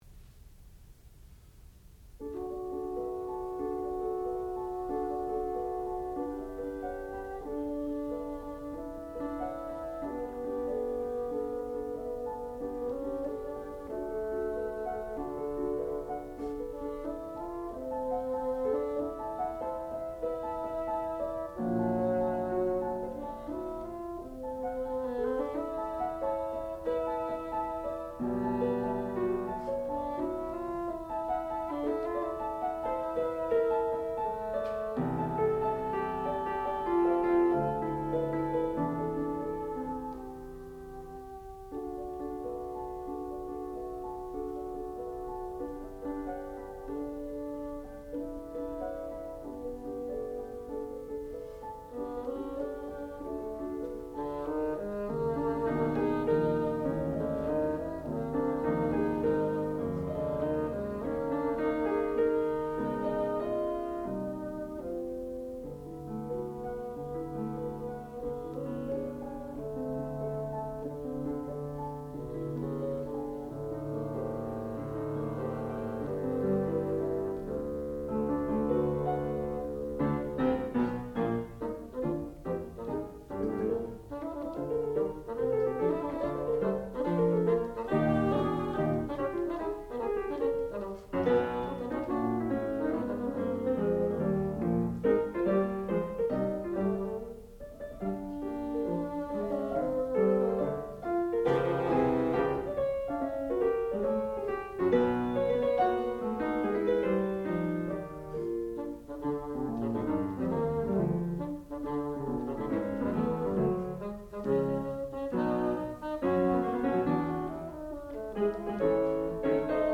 Sonata for Bassoon and Piano
sound recording-musical
classical music
piano
bassoon